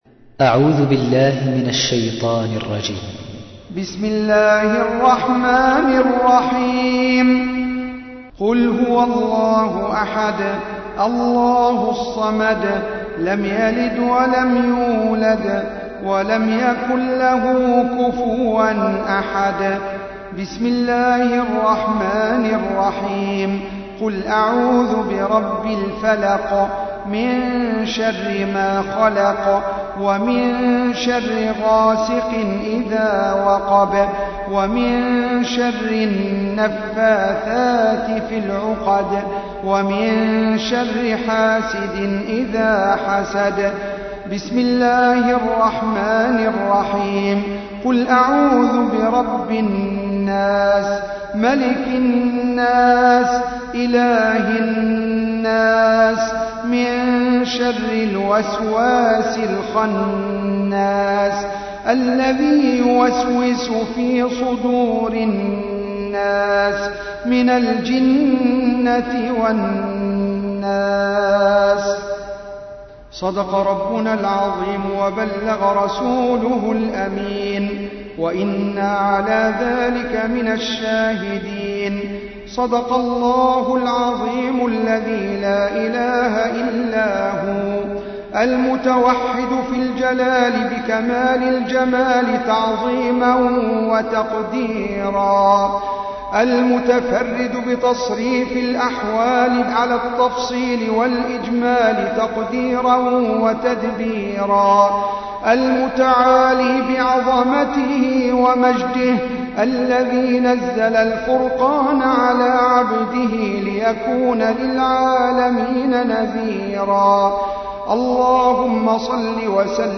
تسجيل لدعاء ختم القرآن الكريم